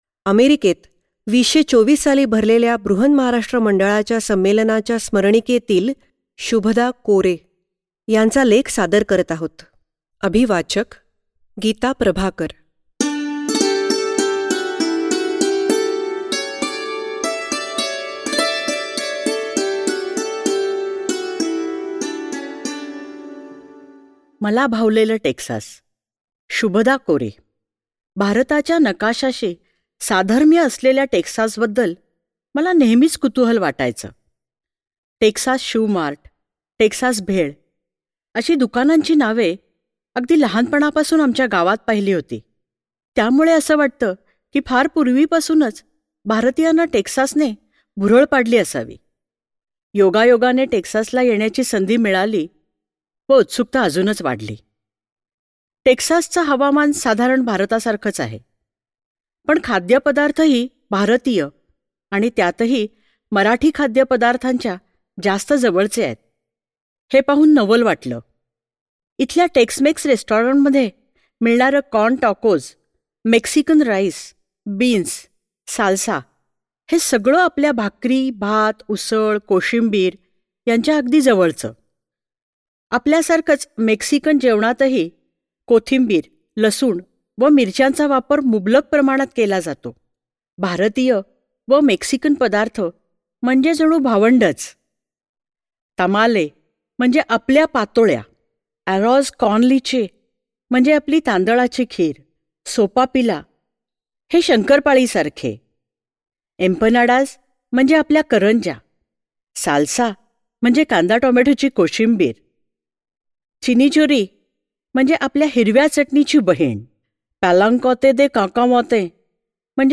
ईबुक आणि ओडिओ बुक..
आणि स्पष्ट मराठीत अभिवाचन ऐकता येते आहे.